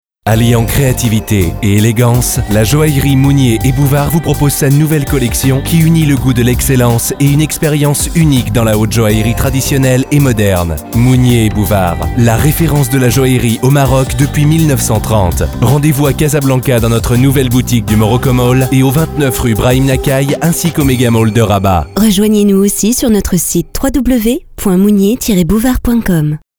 Pymprod réalise pour vous des spots publicitaires avec nos voix off masculines et féminines.
Pymprod a réalisé un spot publicitaire vantant les mérites de leurs créations.